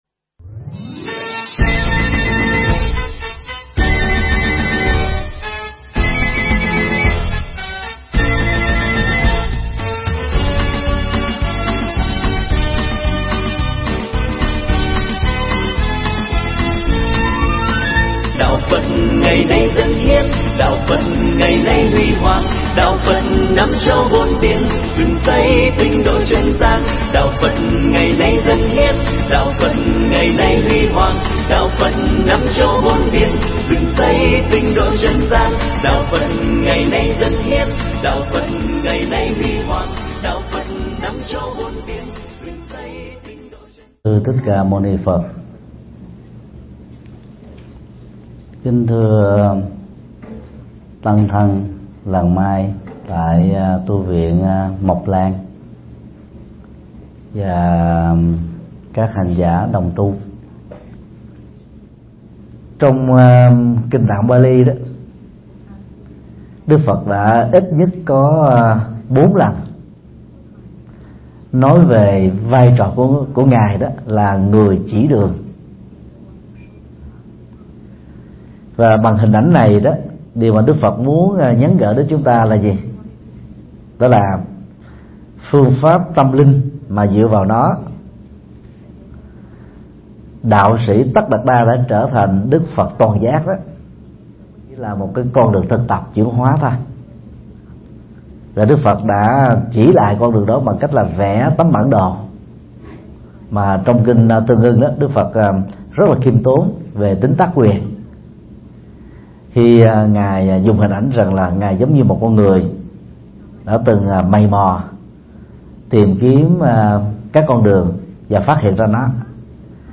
Mp3 Thuyết Giảng Cách Phật Dạy Chấm Dứt Khổ Đau